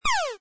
saucershoot.mp3